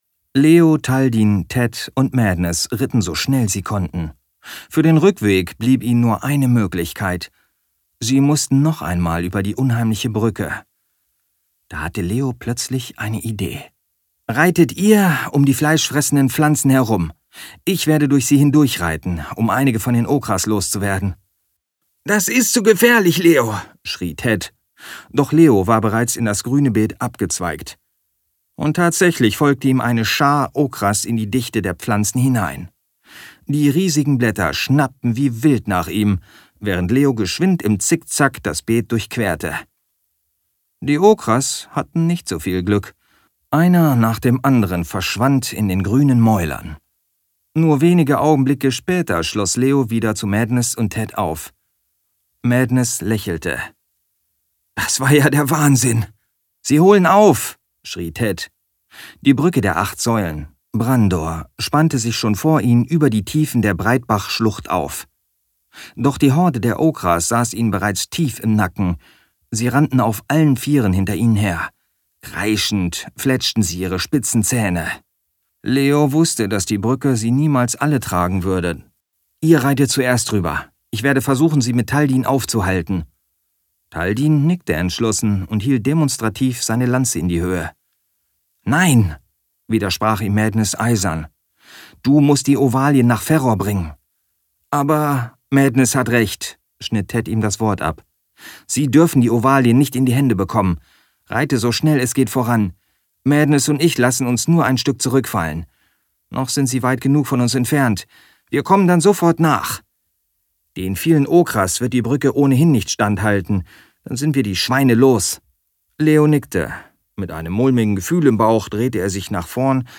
Endlautstärke Hörbuch
Liege hier bei rund -17 lufs. Es ist übrigens ein Jungend Fantasy Roman der erst im September erscheint.